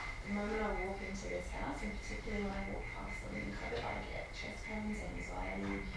EVP 5 – Probably the clearest EVP we have captured on this investigation.